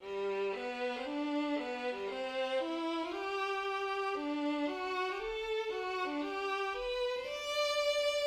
The first movement is characterized by its bold and heroic introduction.
1st theme (C minor to G major)